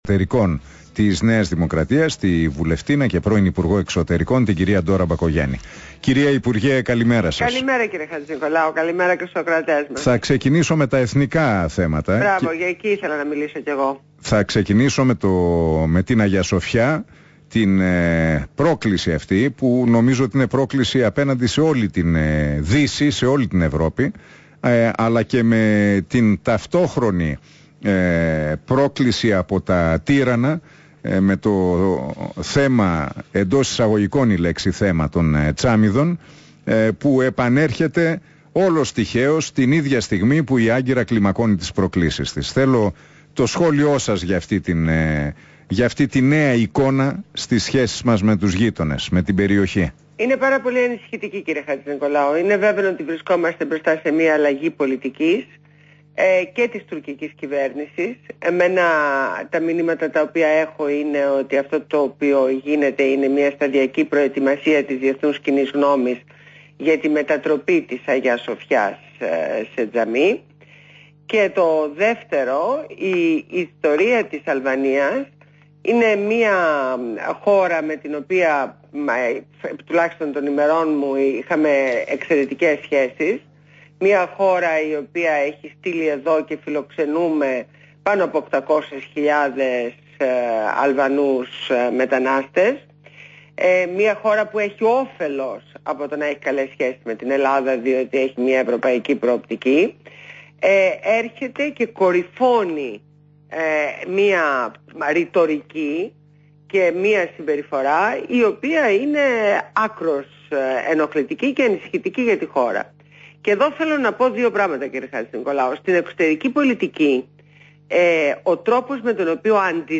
Συνέντευξη στο ραδιόφωνο REAL fm στο δημοσιογράφο Ν. Χατζηνικολάου.